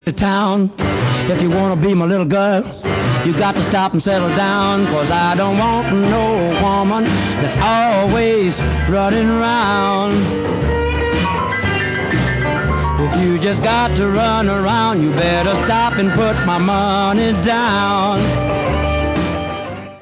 The best r&b guitarist in the world!? he did it all!!